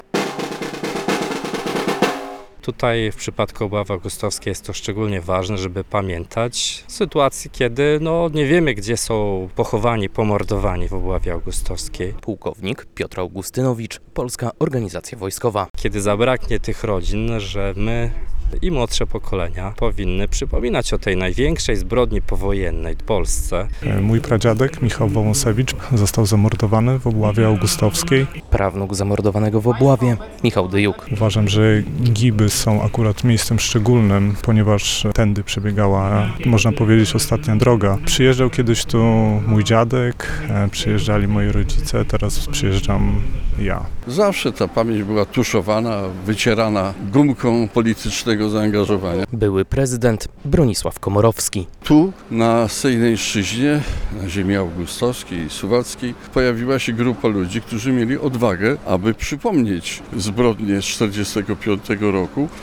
Mszą świętą, uroczystym apelem oraz złożeniem kwiatów i wieńców przy Wzgórzu Krzyży rodziny ofiar, okoliczni mieszkańcy oraz przedstawiciele władz uczcili w niedzielę (21.07) w Gibach 79. rocznicę obławy augustowskiej.